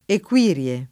vai all'elenco alfabetico delle voci ingrandisci il carattere 100% rimpicciolisci il carattere stampa invia tramite posta elettronica codividi su Facebook Equirrie [ ek U& rr L e ] o Equirie [ ek U& r L e ] n. pr. f. pl. stor.